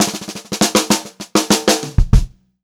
96CL3FILL1-R.wav